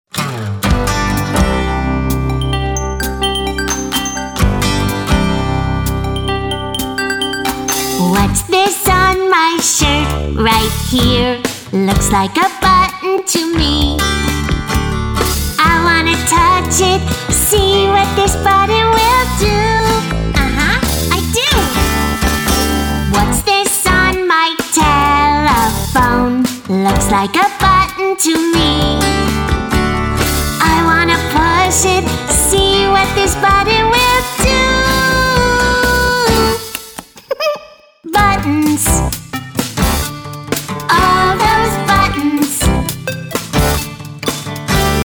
kid funk, blues, pop, rock, country, anthem and ballad